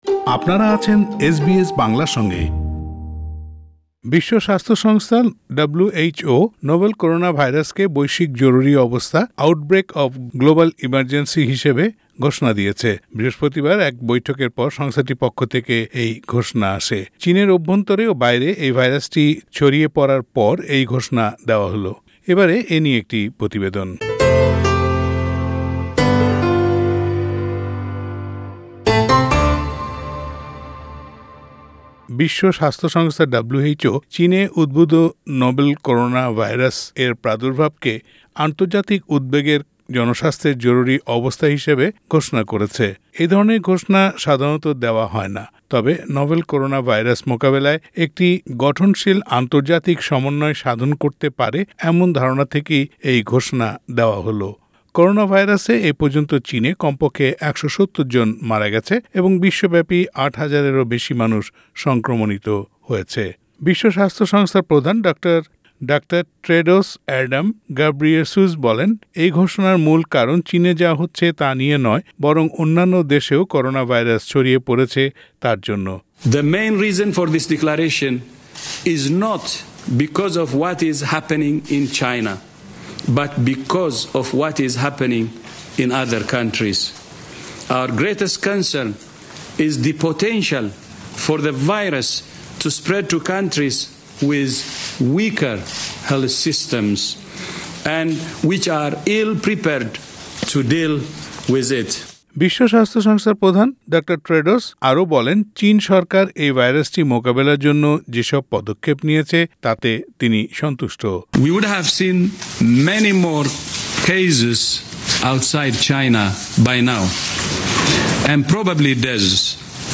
বিশ্বব্যাপী জরুরি অবস্থা ঘোষণার মধ্য দিয়ে এই ভাইরাস এক দেশ থেকে অন্য দেশে ছড়িয়ে না পরে সে ব্যাপারে সতর্ক থাকার সুপারিশ করা হয়েছে। করোনা ভাইরাস নিয়ে প্রতিবেদনটি শুনতে উপরের অডিও প্লেয়ারে চাপ দিন।